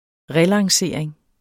Udtale [ ˈʁε- ]